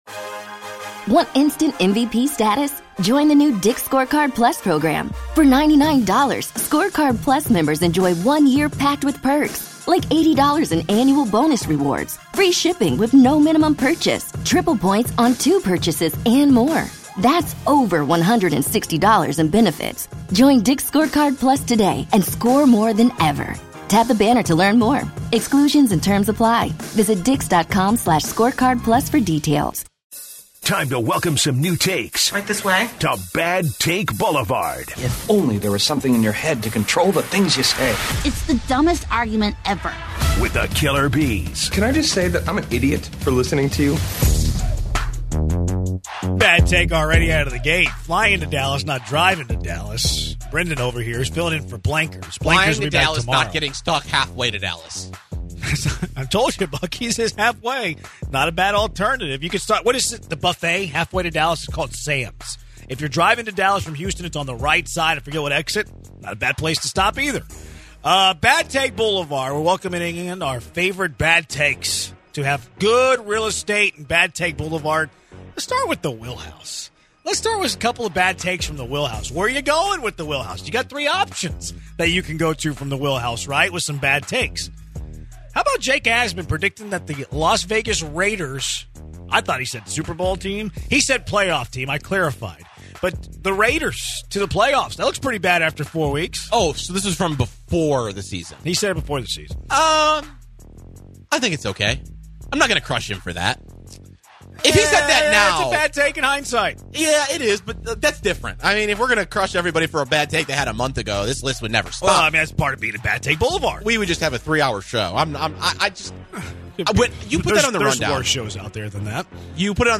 What is the best fast food burger? The conversation gets good as the guys give you their favorites.